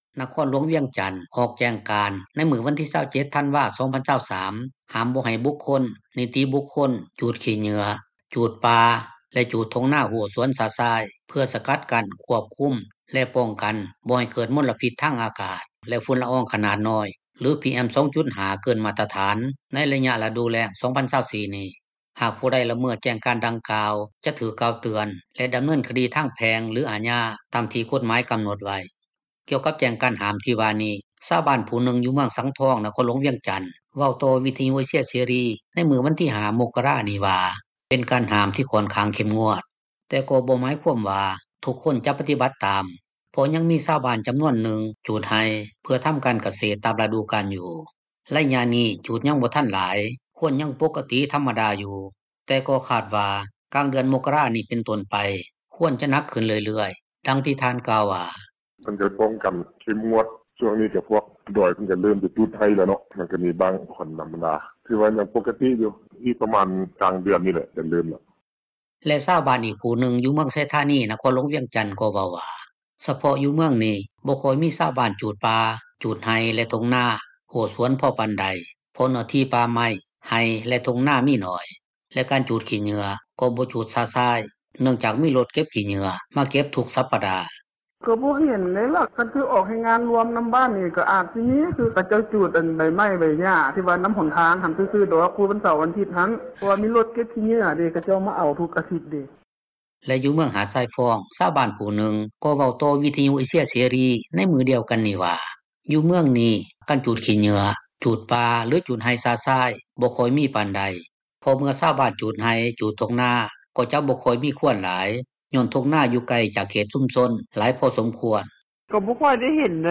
ກ່ຽວກັບແຈ້ງການຫ້າມ ທີ່ວ່ານີ້ ຊາວບ້ານຜູ້ນຶ່ງຢູ່ເມືອງສັງທອງ ນະຄອນຫລວງວຽງຈັນ ເວົ້າຕໍ່ວິທຍຸເອເຊັຽເສຣີ ໃນມື້ວັນທີ 5 ມົກກະຣານີ້ວ່າ ເປັນການຫ້າມ ທີ່ຂ້ອນຂ້າງເຂັ້ມງວດ, ແຕ່ກໍບໍ່ໝາຍຄວາມວ່າ ທຸກຄົນຈະປະຕິບັດຕາມ ເພາະຍັງມີຊາວບ້ານຈໍານວນນຶ່ງ ຈູດໄຮ່ ເພື່ອທໍາການກະເສດ ຕາມຣະດູການຢູ່.